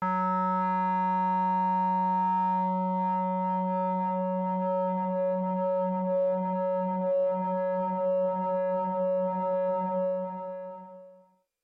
描述：通过Modular Sample从模拟合成器采样的单音。
标签： MIDI-速度-21 F4 MIDI音符-66 挡泥板-色度北极星 合成器 单票据 多重采样
声道立体声